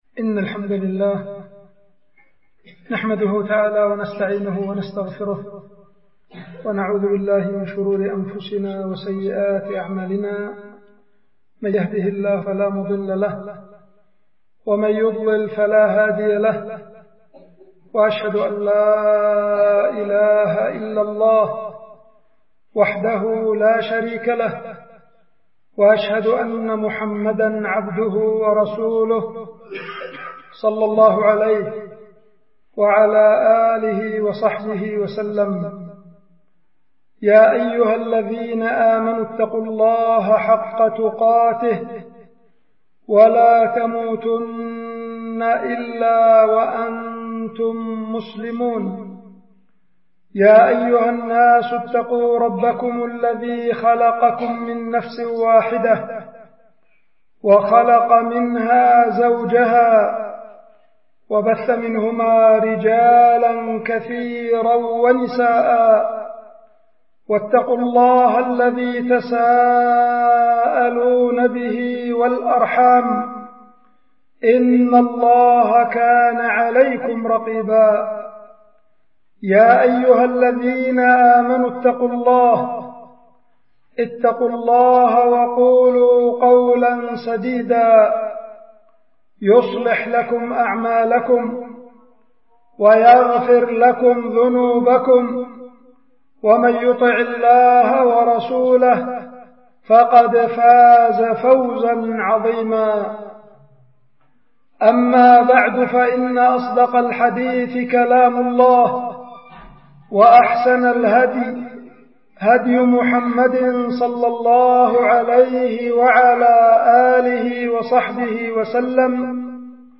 القيت في مسجد العدنة بالعزلة- مديرية بعدان- إب-اليمن
خطبة